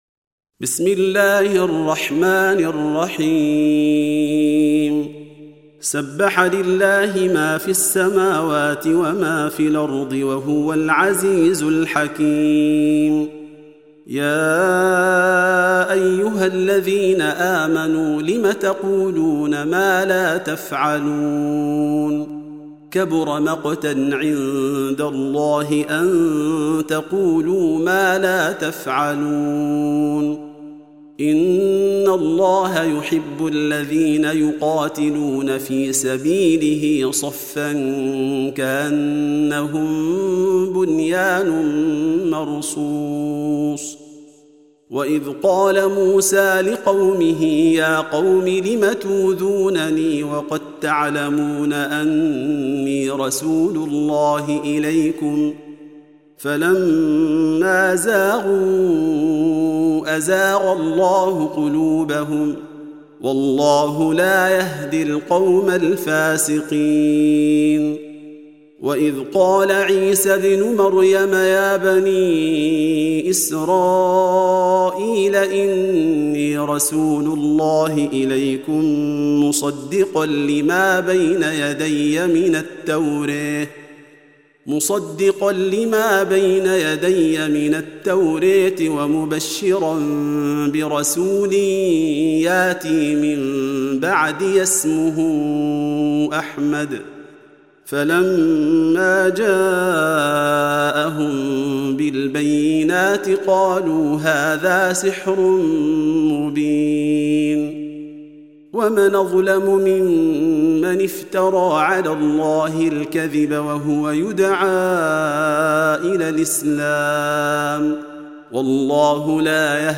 Surah Repeating تكرار السورة Download Surah حمّل السورة Reciting Murattalah Audio for 61. Surah As-Saff سورة الصف N.B *Surah Includes Al-Basmalah Reciters Sequents تتابع التلاوات Reciters Repeats تكرار التلاوات